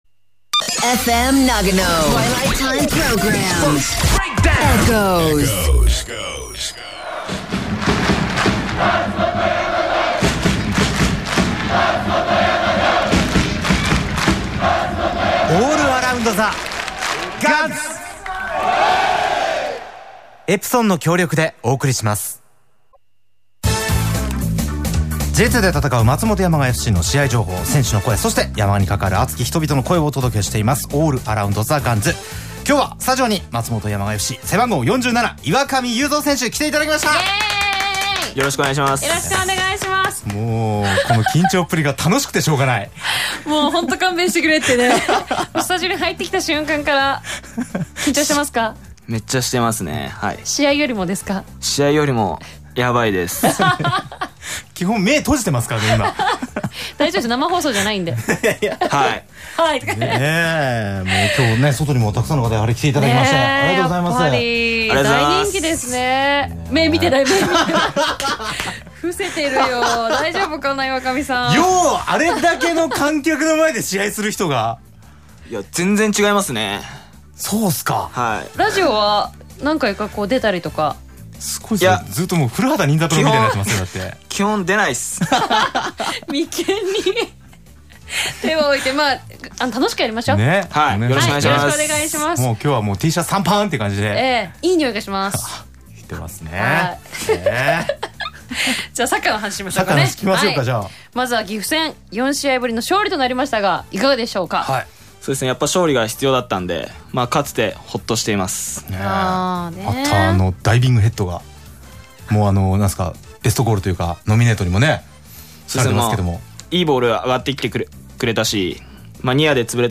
岩上祐三選手生出演！